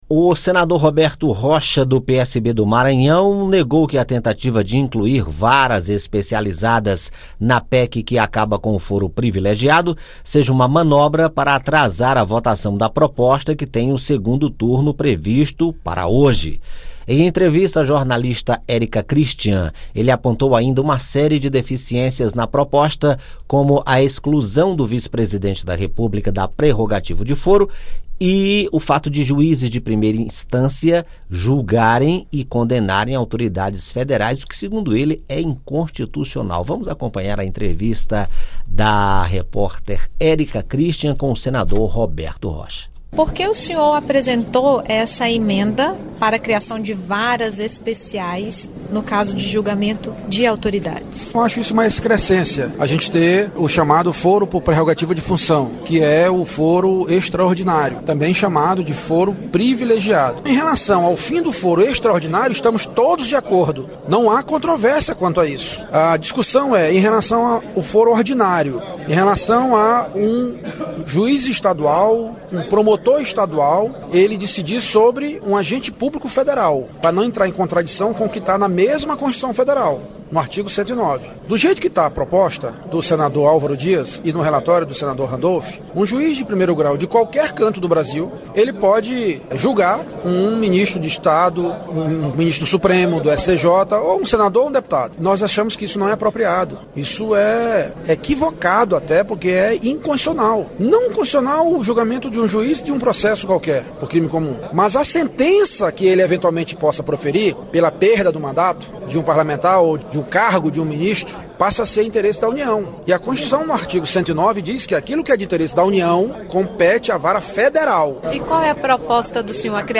O senador Roberto Rocha (PSB-MA) defendeu a criação de varas especiais para o julgamento de autoridades e negou que a emenda apresentada por ele tenha como objetivo atrasar a votação da proposta de emenda à Constituição que põe fim ao foro privilegiado (PEC 10/2013). Em entrevista